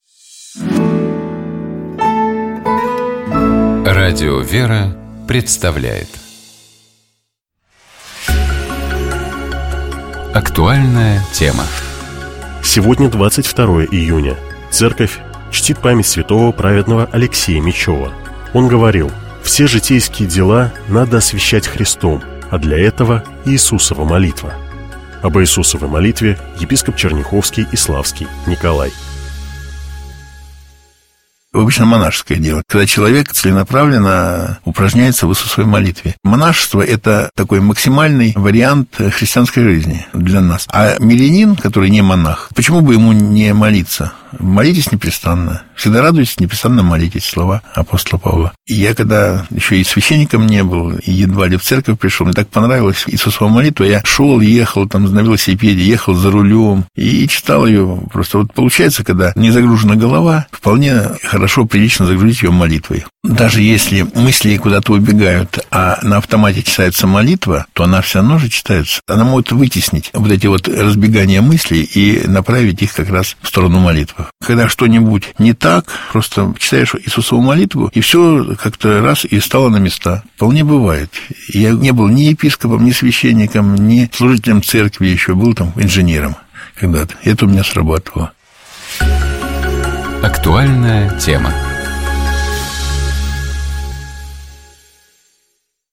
Об Иисусовой молитве, — епископ Черняховский и Славский Николай.